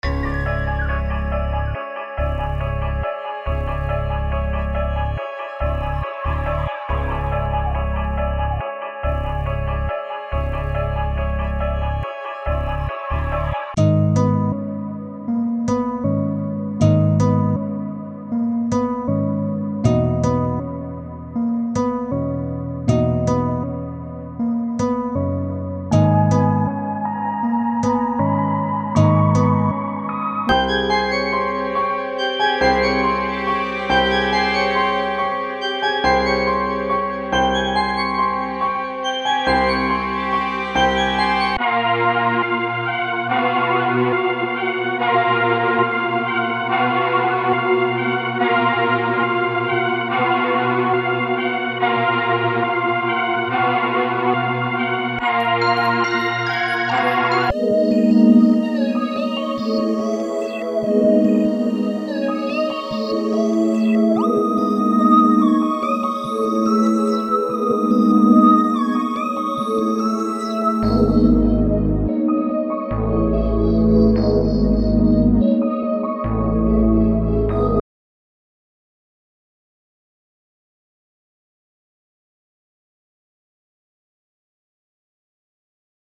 cozy, head-nod vibe